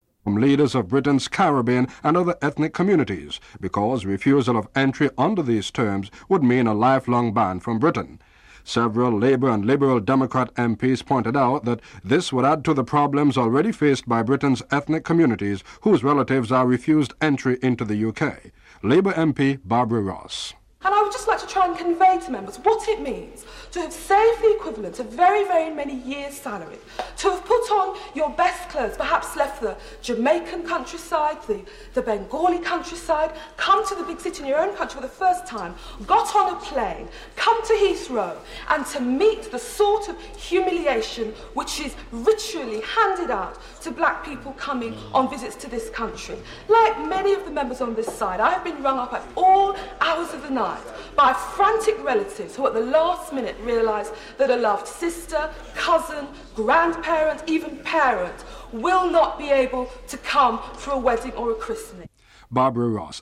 The British Broadcasting Corporation
Peter Sutherland, GATT Director General stresses the urgent need for a conclusion.